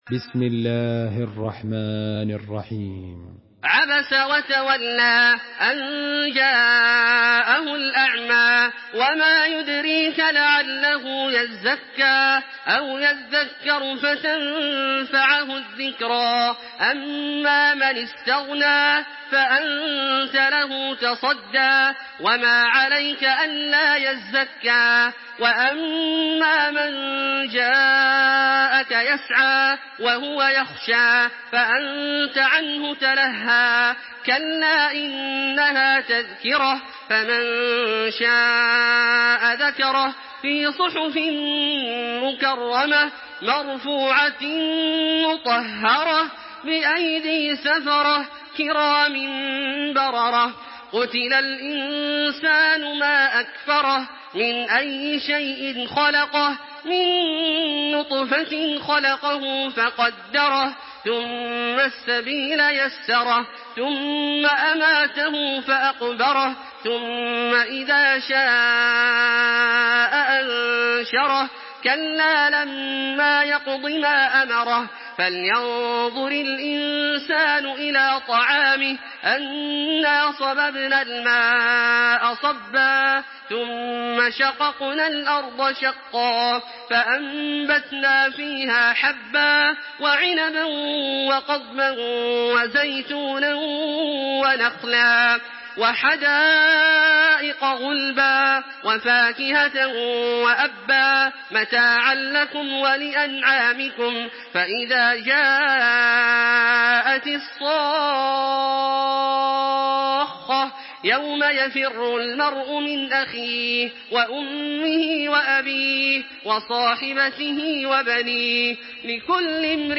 Surah Abasa MP3 by Makkah Taraweeh 1426 in Hafs An Asim narration.
Murattal